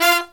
Index of /90_sSampleCDs/USB Soundscan vol.29 - Killer Brass Riffs [AKAI] 1CD/Partition F/03-HIGHHITS2
HIGH HIT29-L.wav